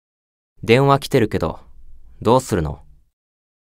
File:Belphegor Call Notification Voice.ogg
Belphegor_Call_Notification_Voice.ogg.mp3